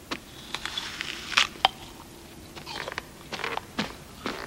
• BITING AN APPLE.wav
BITING_AN_APPLE_Mww.wav